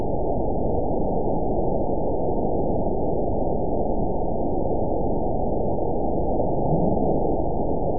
event 922005 date 12/25/24 time 01:27:31 GMT (4 months, 1 week ago) score 8.22 location TSS-AB02 detected by nrw target species NRW annotations +NRW Spectrogram: Frequency (kHz) vs. Time (s) audio not available .wav